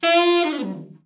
ALT FALL  14.wav